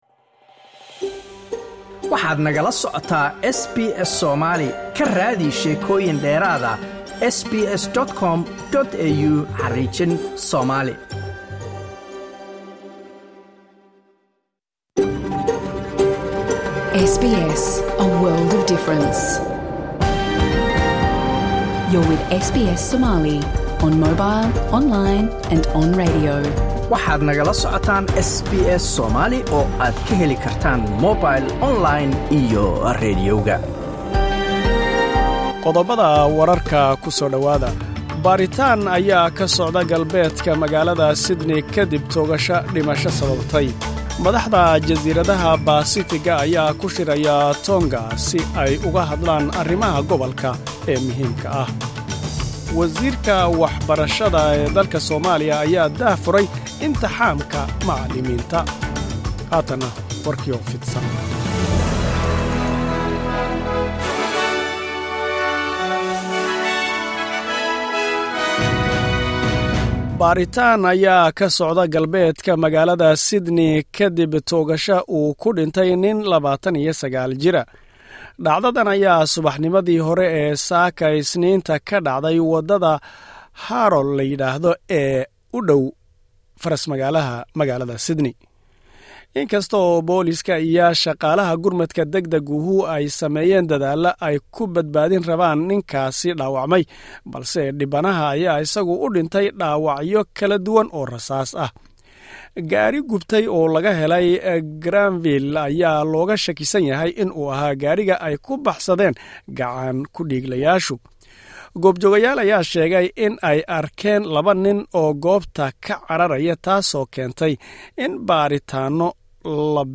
WARARKA SBS SOMALI, 26 AUGUST.